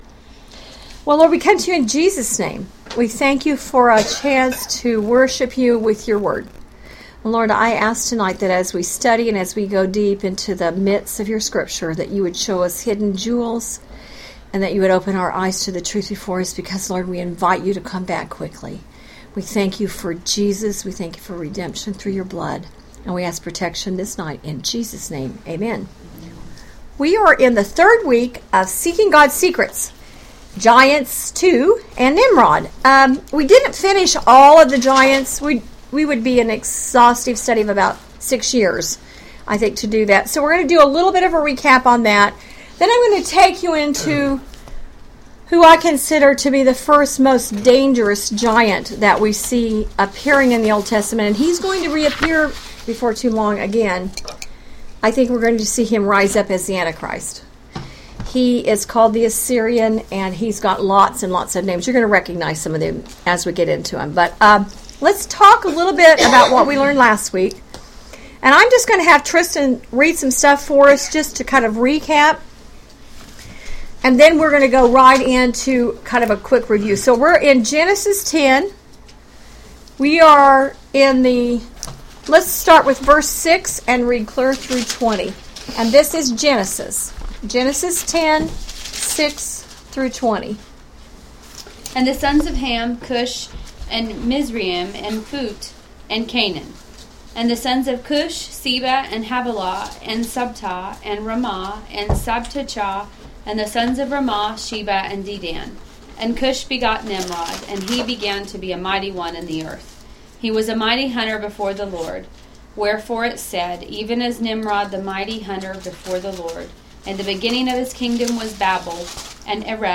Bible Study Audio